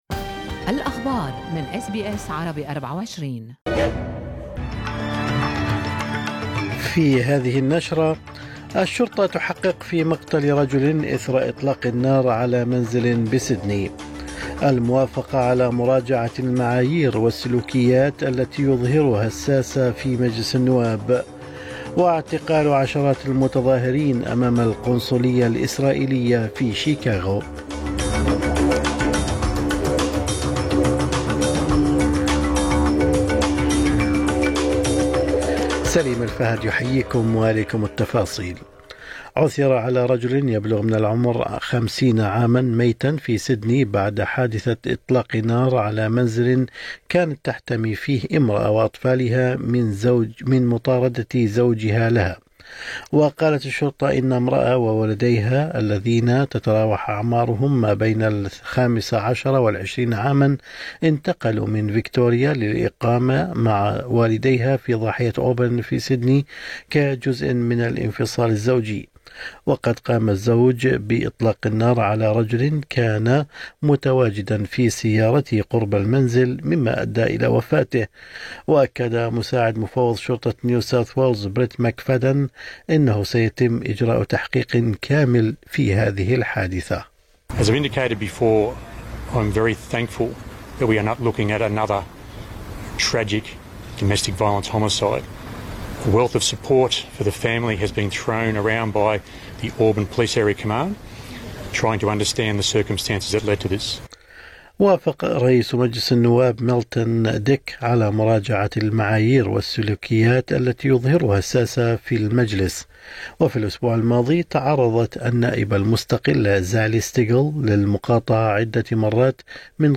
نشرة أخبار الصباح 22/8/2024